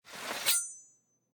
general / combat / weapons / default_swingable / draw1.ogg
draw1.ogg